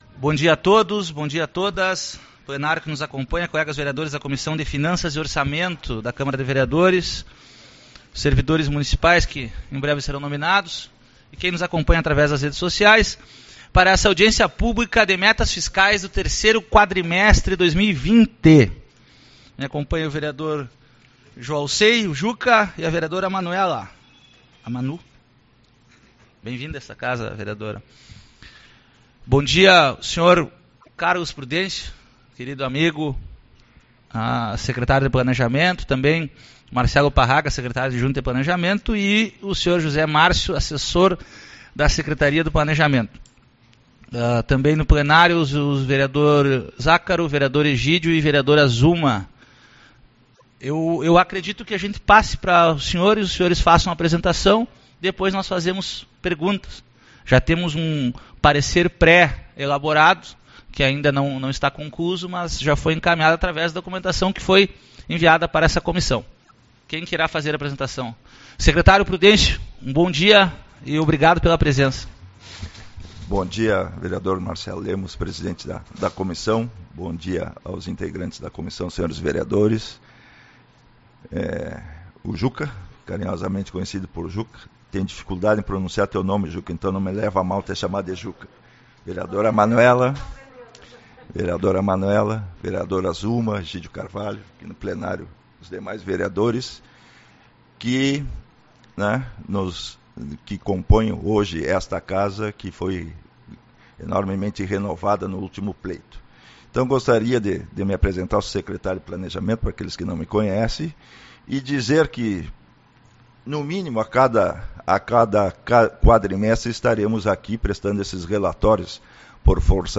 26/02 - Audiência Pública-Metas Fiscais